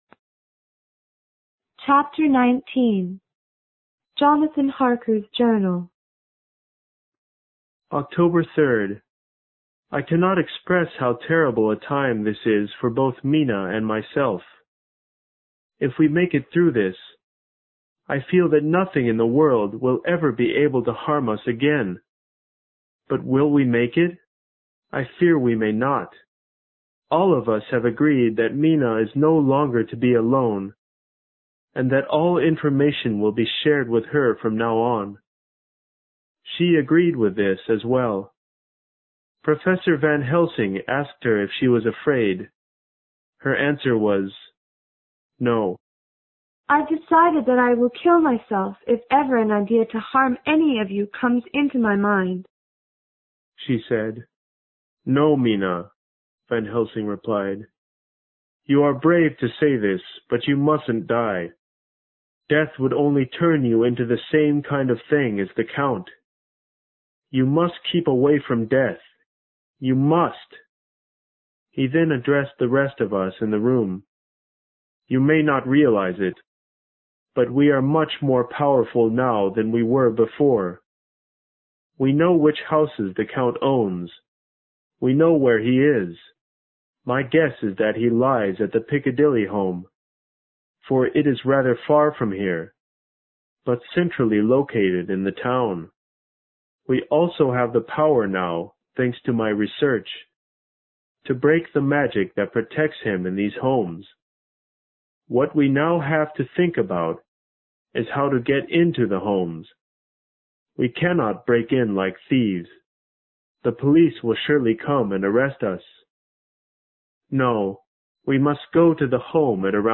有声名著之吸血鬼 Chapter19 听力文件下载—在线英语听力室